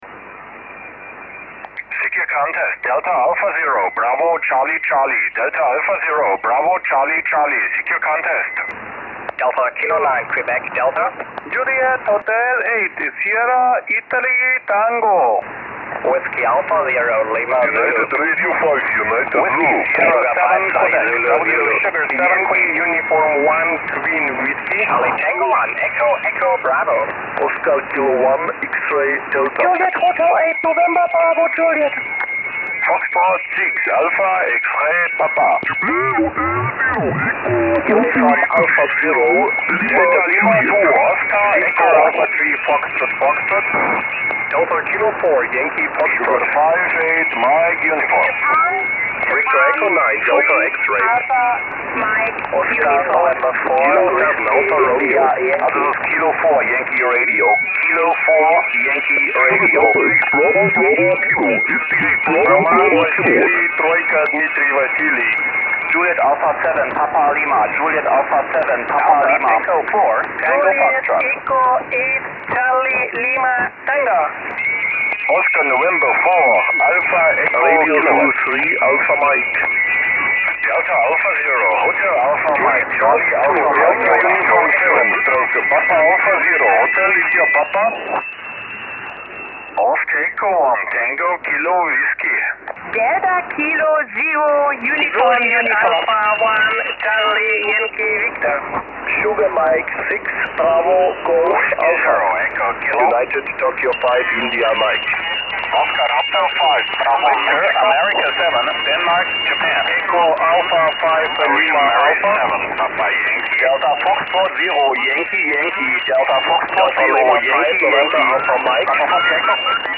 Also wurden zwei PileUps über 3 Minuten simuliert (alles selbstgesprochen – die japanischen Rufzeichen sollen am meisten Spaß gemacht haben).